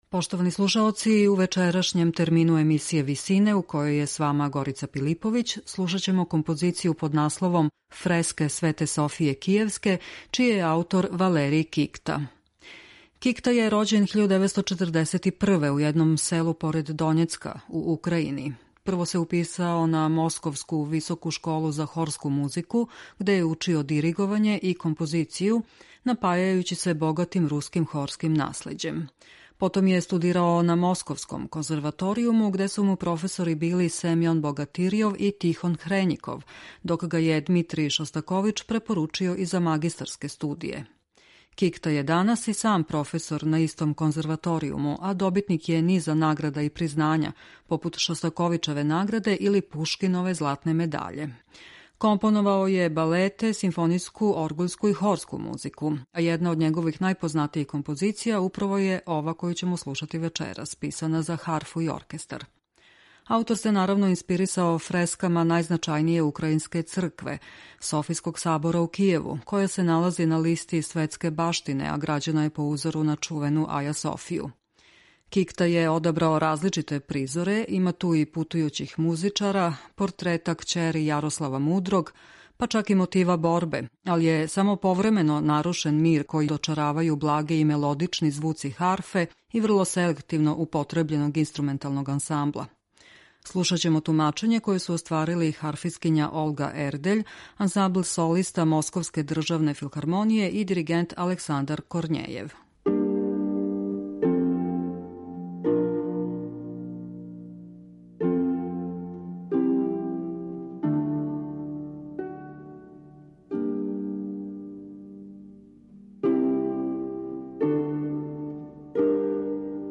концертантну симфонију